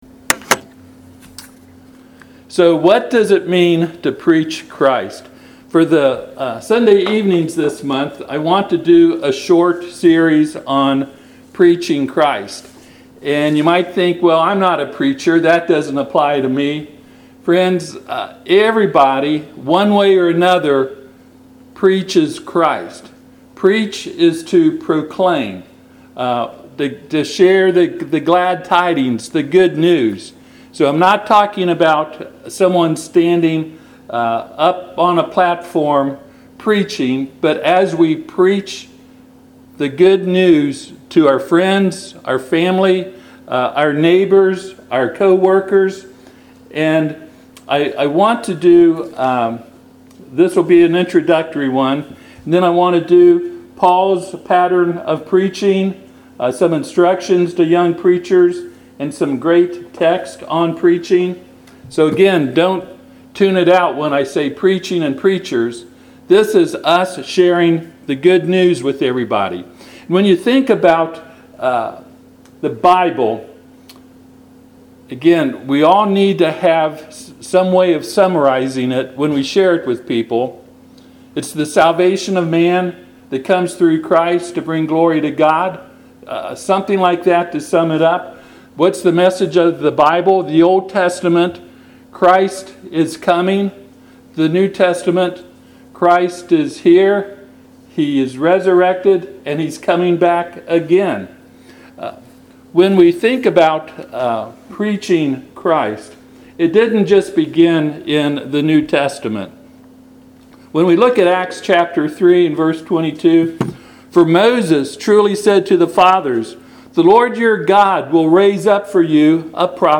Passage: Acts 3:22-24 Service Type: Sunday PM